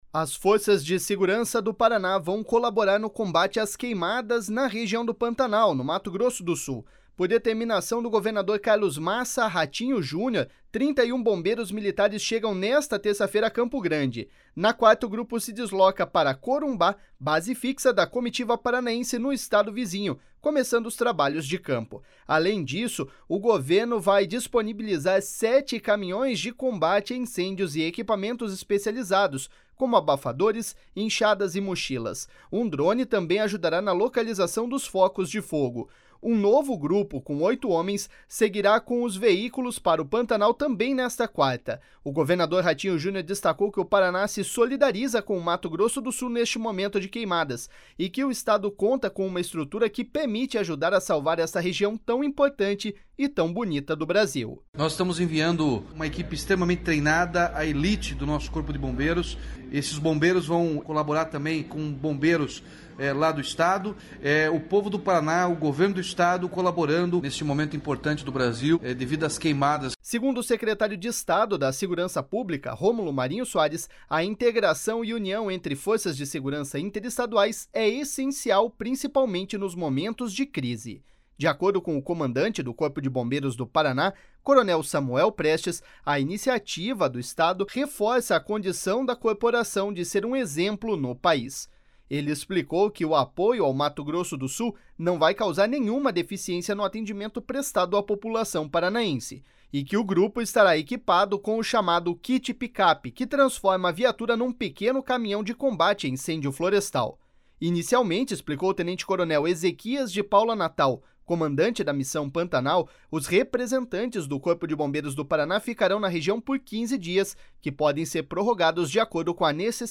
O governador Ratinho Junior destacou que o Paraná se solidariza com o Mato Grosso do Sul neste momento de queimadas, e que o Estado conta com uma estrutura que permite ajudar a salvar esta região tão importante e tão bonita do Brasil.// SONORA RATINHO JUNIOR.//